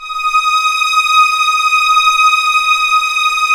Index of /90_sSampleCDs/Roland - String Master Series/STR_Vlns 7 Orch/STR_Vls7 p%f St